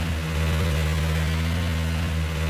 boatengine_revloop.wav